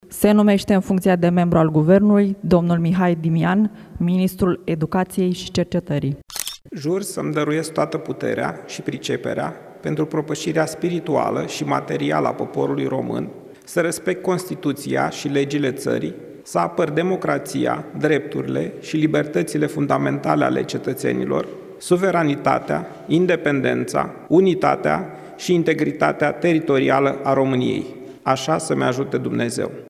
Mihai Dimian a depus jurământul de învestitură în funcţia de ministru al Educaţiei, marţi, în cadrul unei ceremonii desfăşurate la Palatul Cotroceni, în prezenţa preşedintelui Nicuşor Dan.